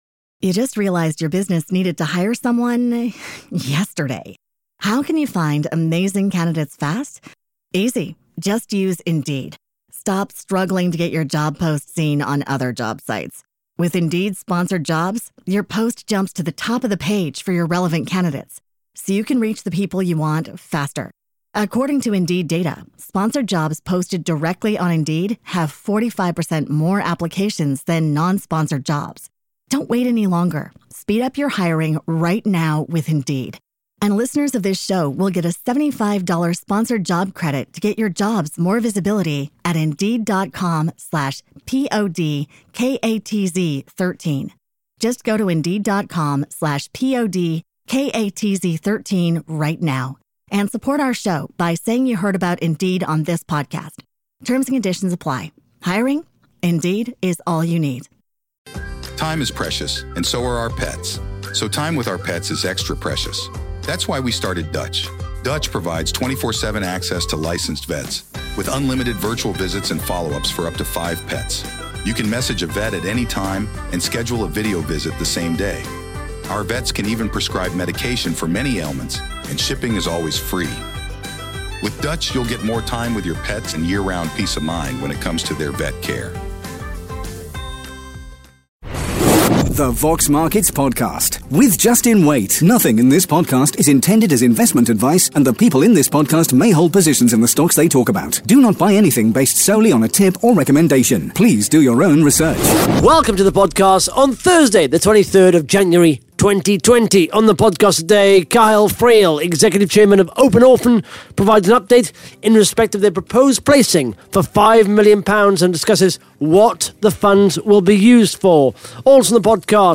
(Interview starts at 12 minutes 28 seconds)